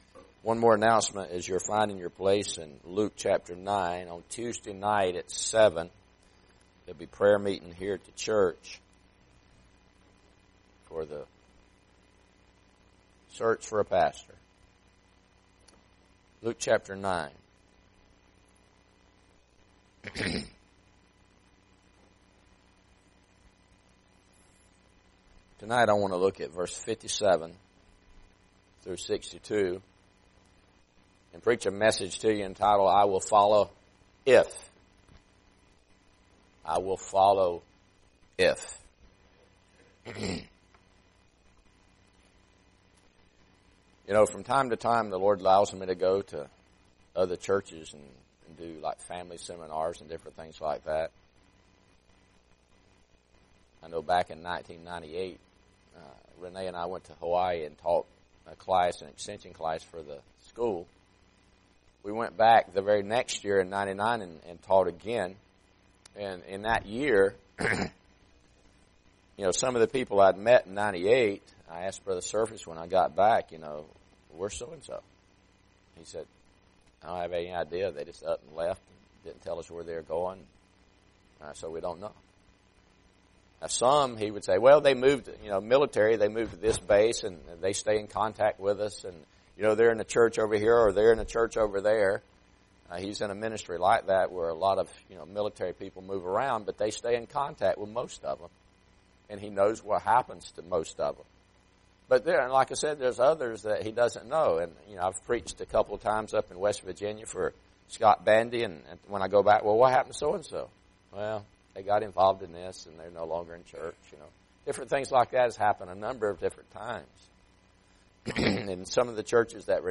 Luke 9:57-62 Service Type: Sunday PM Bible Text